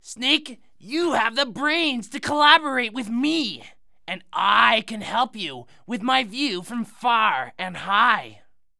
Crow Sounds
The following is a list of the pre-recorded sounds that the unembodied Crow uttered in order to entice participants to come close enough to embody as Crow.